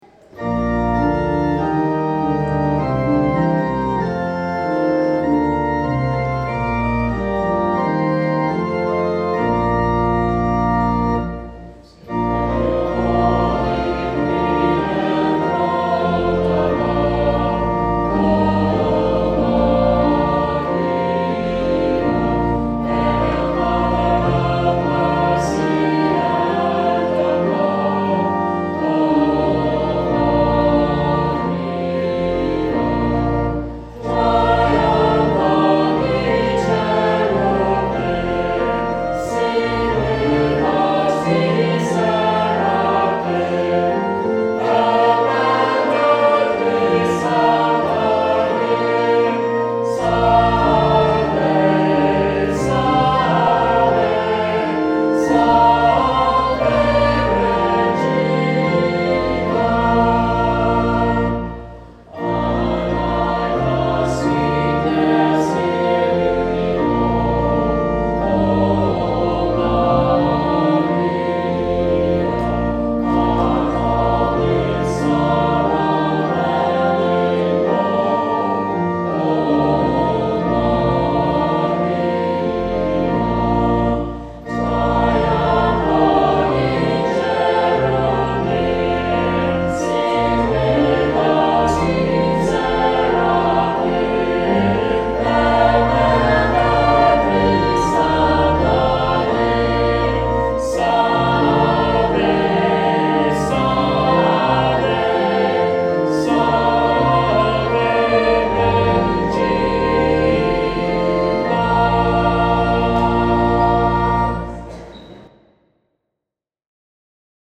Saint Clement Choir Sang this Song